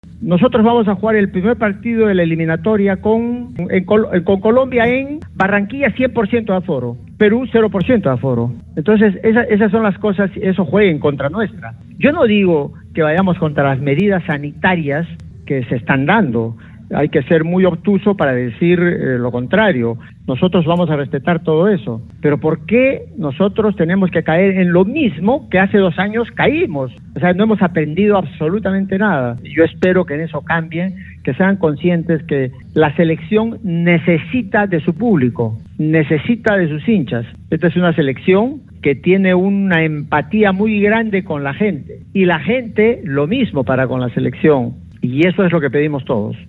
(Juan Carlos Oblitas, director deportivo de la Federación Peruana de Fútbol)
Juan-Carlos-Oblitas-gerente-deportivo-FPF-en-RPP..mp3